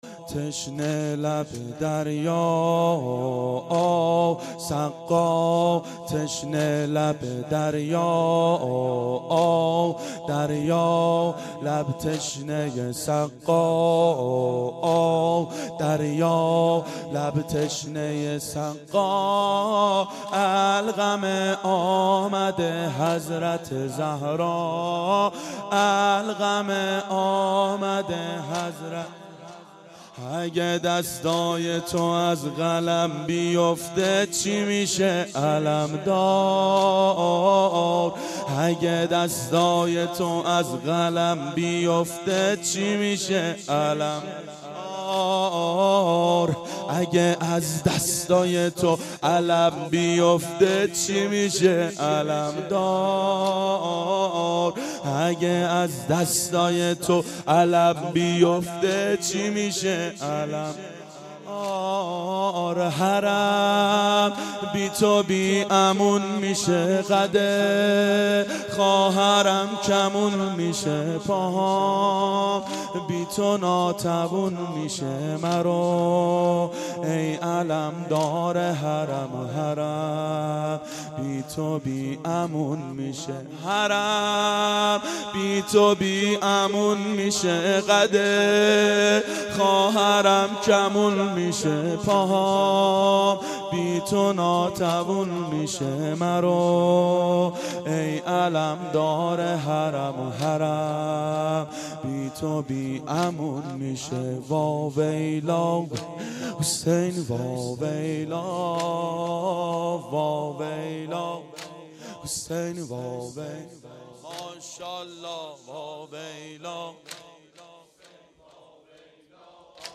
• شب تاسوعا 92 هیأت عاشقان اباالفضل علیه السلام منارجنبان